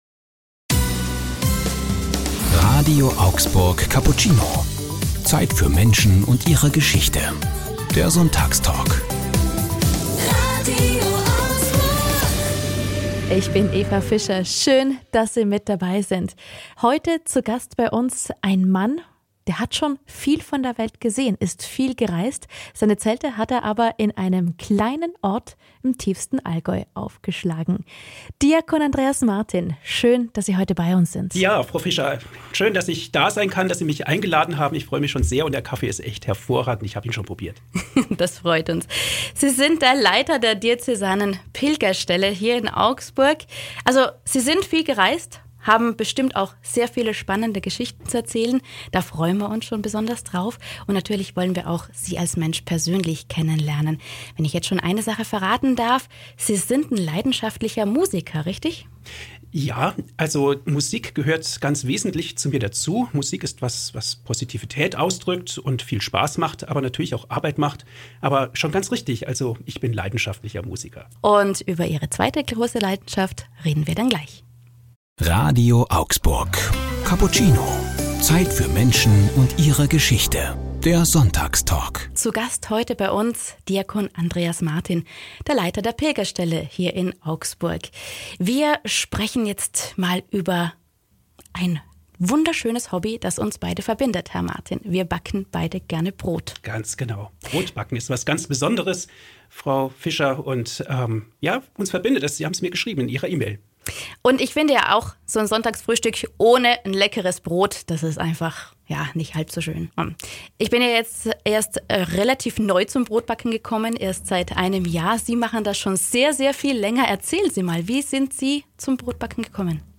Hier gibts den ganzen Sonntagstalk zum Nachhören.